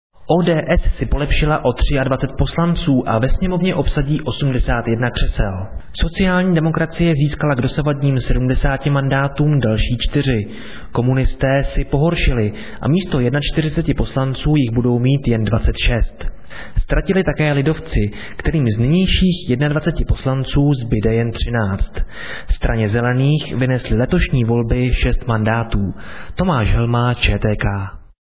zvukove_zpravodajstvi_ukazka.mp3